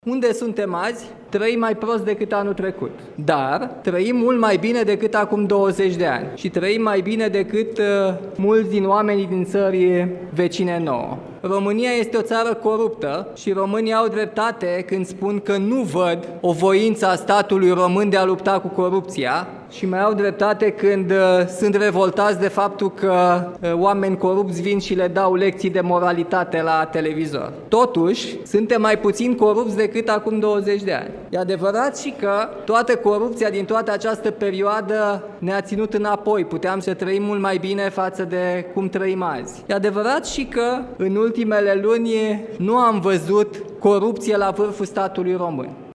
O radiografie a României – așa a arătat discursul președintelui Nicușor Dan din deschiderea recepției de Ziua Națională de la Palatul Cotroceni.